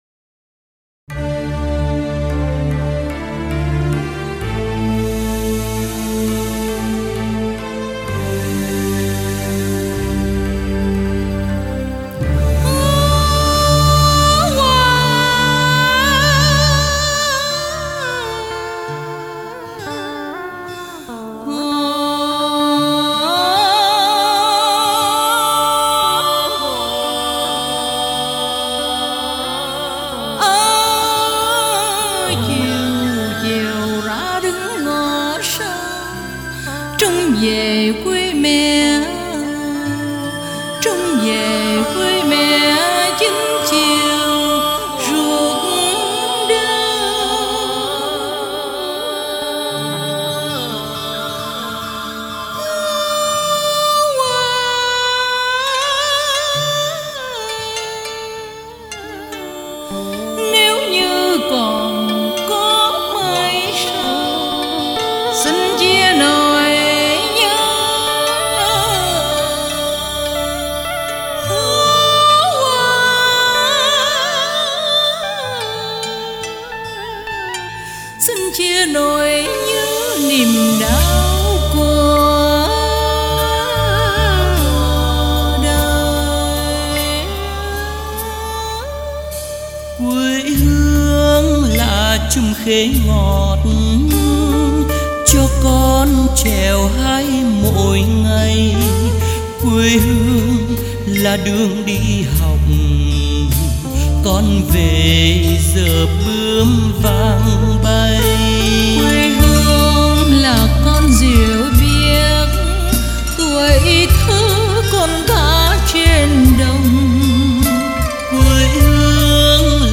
Thể loại: Tân cổ